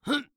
CK格挡04.wav
人声采集素材/男2刺客型/CK格挡04.wav